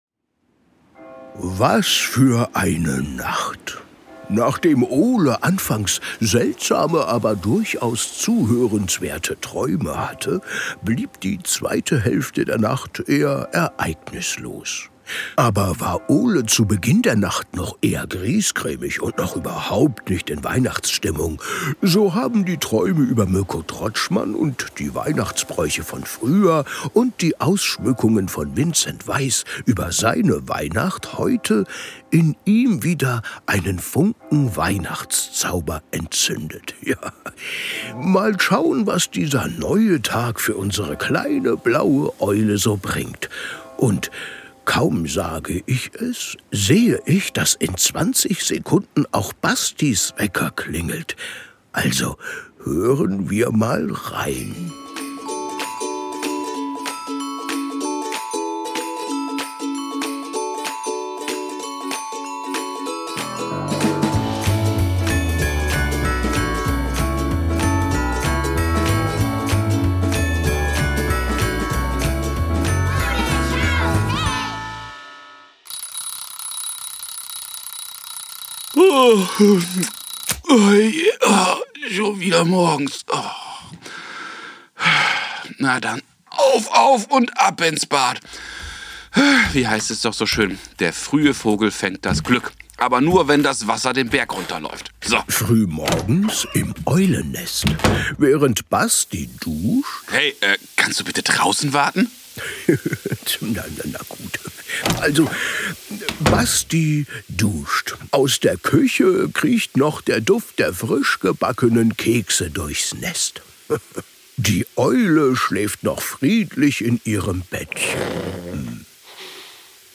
mit der Schauspielerin Collien Ulmen-Fernandes über Traditionen, Erinnerungen und virtuelle Weihnachten in der Zukunft